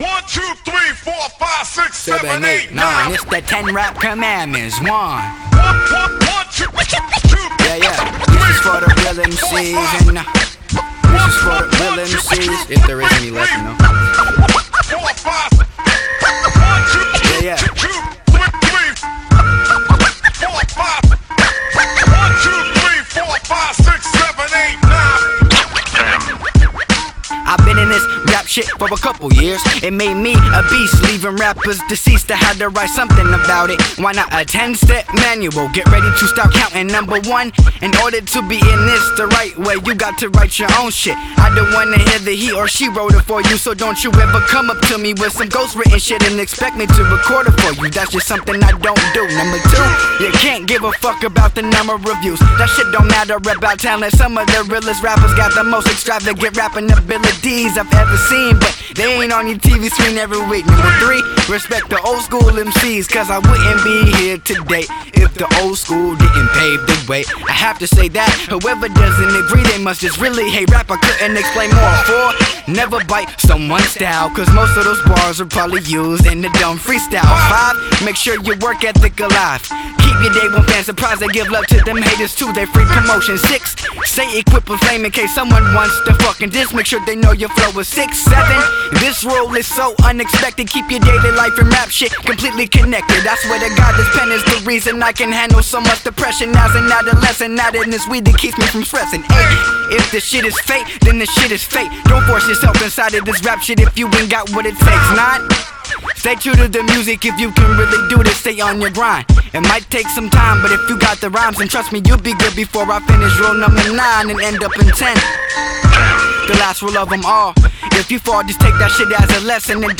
With a tongue-twisting cadence